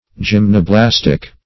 Search Result for " gymnoblastic" : The Collaborative International Dictionary of English v.0.48: Gymnoblastic \Gym`no*blas"tic\ (j[i^]m`n[-o]*bl[a^]s"t[i^]k), a. (Zool.) Of or pertaining to the Gymnoblastea.